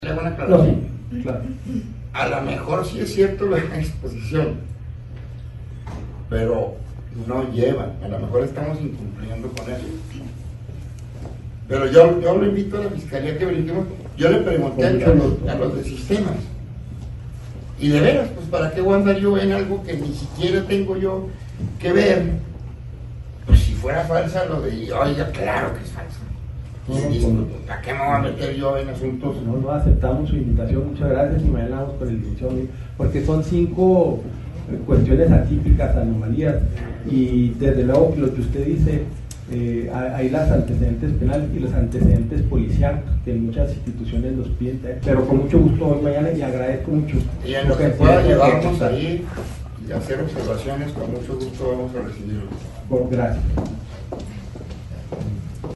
Chihuahua, Chih.- El fiscal General del Estado, César Jáuregui Moreno, durante su comparecencia en el Congreso del Estado, exhortó al poder legislativo reformar la legislación para que sea el Poder Judicial-juzgados- los que encargados de emitir cartas de no antecedentes penales, y desligar la Fiscalía General del Estado de la expedición de las mismas.